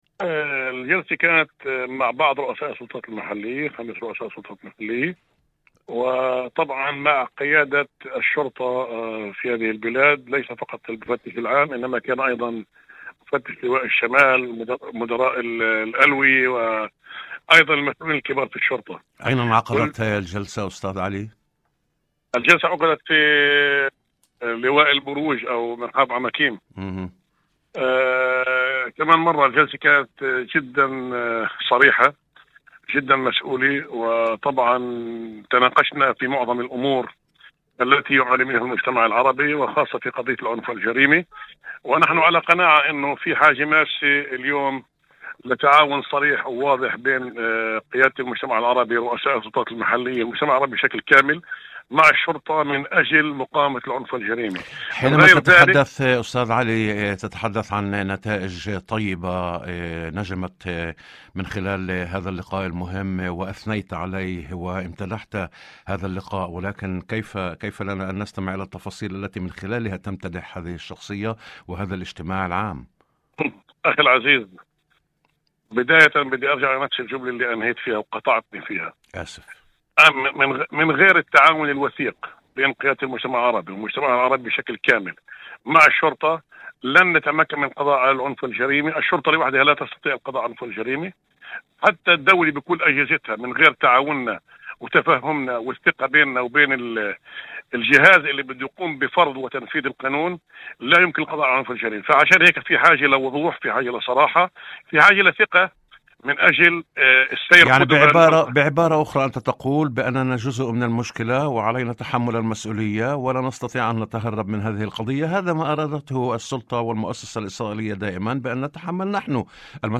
وأضاف في مداخلة هاتفية برنامج "يوم جديد"، على إذاعة الشمس، أن الاجتماع انعقد في لواء الشمال، وشدد على أن مواجهة الجريمة تتطلب "تعاونًا صريحًا وواضحًا بين قيادة المجتمع العربي ورؤساء السلطات المحلية والشرطة".